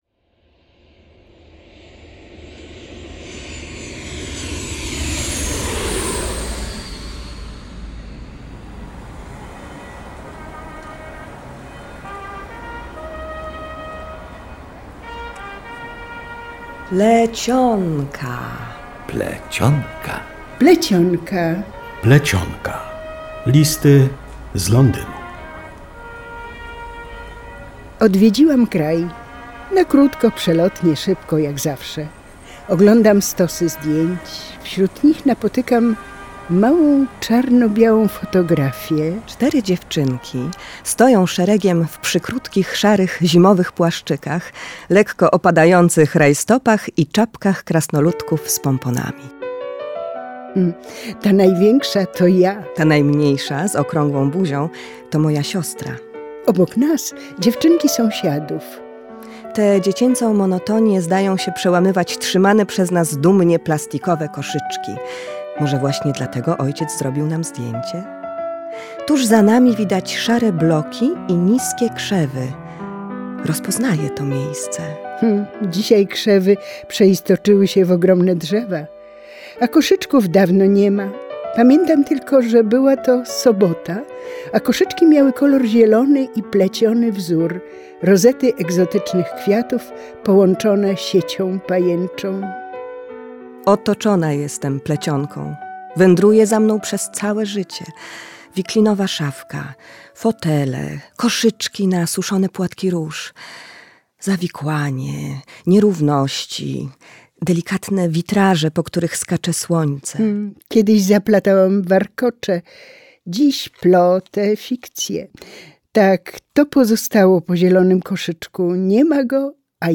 Scenariusz tego premierowego słuchowiska to adaptacja książki Anny Marii Mickiewicz „Listy z Londynu” opublikowanej przez szczecińskie wydawnictwo FORMA w 2024 roku.